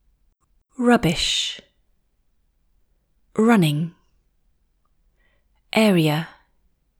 10. Perfecting S endings in English